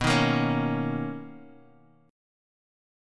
BM9 Chord
Listen to BM9 strummed